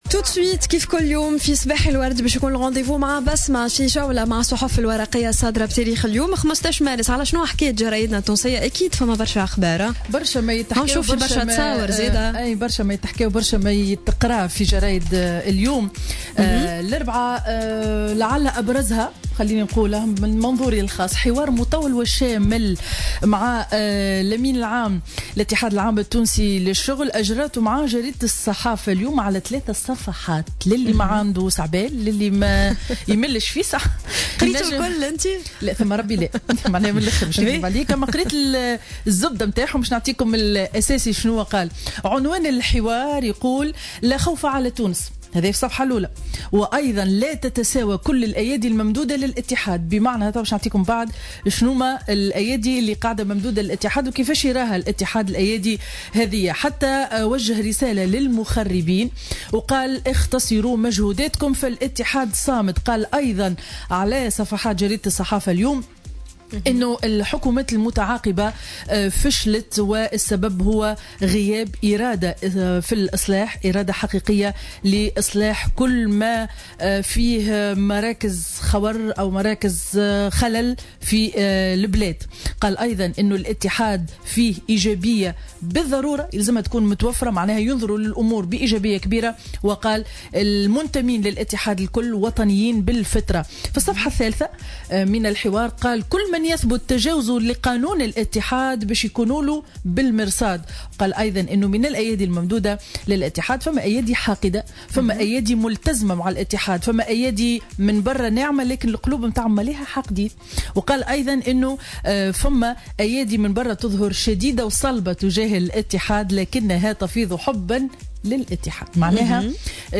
Revue de presse du mercredi 15 mars 2017